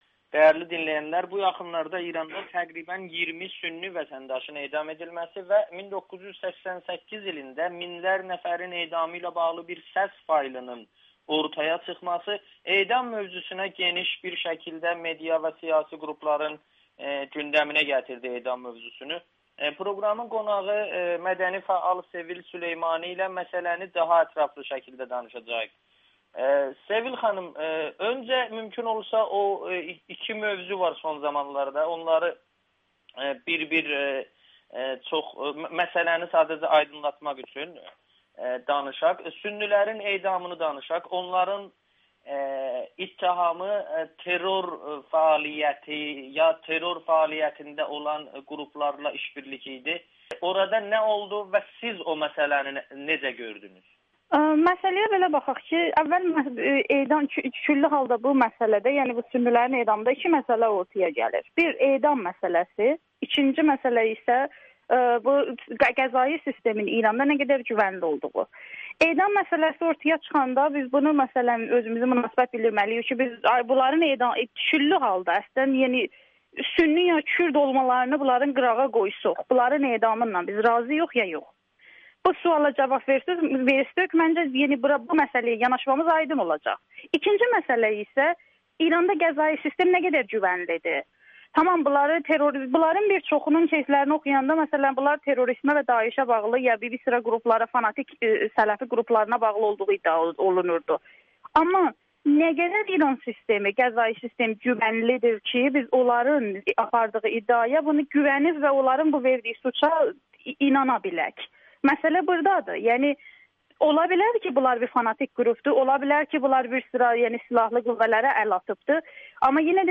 İran cəmiyyətində edam hökmünə zidd olanların sayı azdır [Audio-Müsahibə]